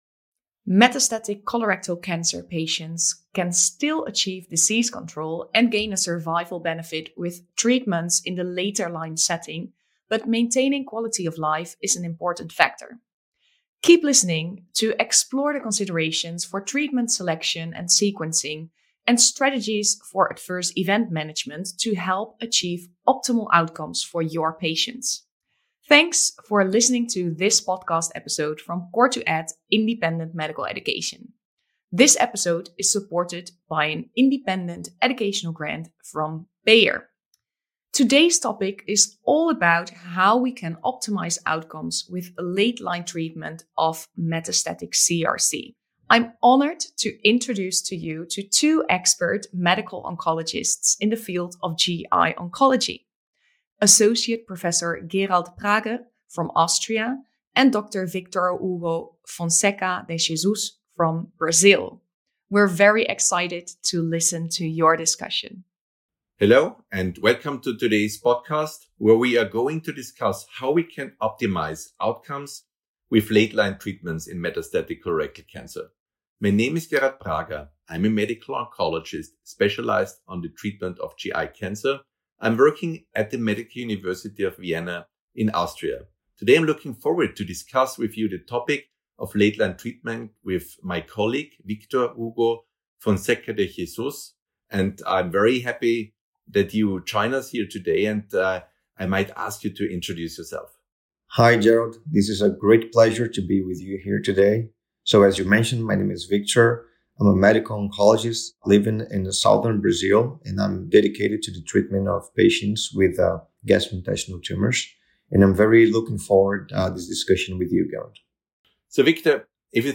If you are able, we encourage you to listen to the audio, which includes emotion and emphasis that is not so easily understood from the words on the page.